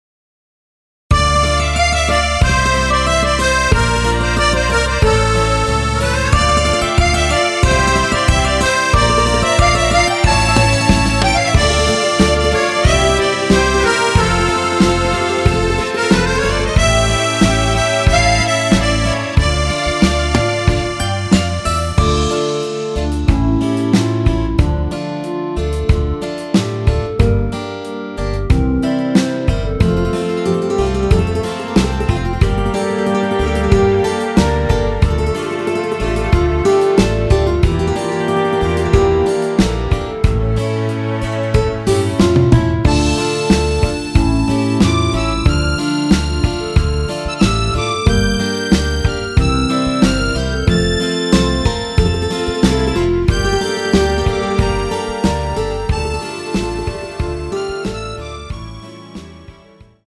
원키 멜로디 포함된 MR입니다.
Dm
멜로디 MR이라고 합니다.
앞부분30초, 뒷부분30초씩 편집해서 올려 드리고 있습니다.
중간에 음이 끈어지고 다시 나오는 이유는